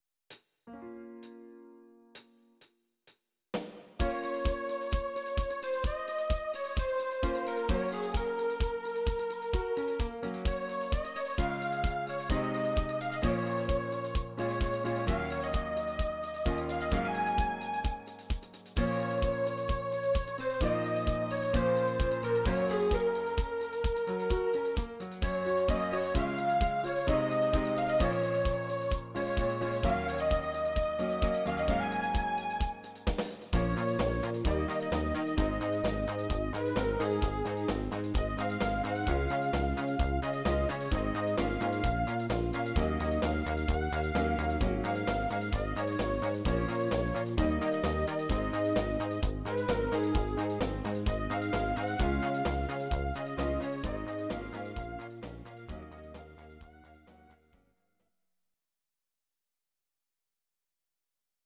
Audio Recordings based on Midi-files
Pop, Disco, 1980s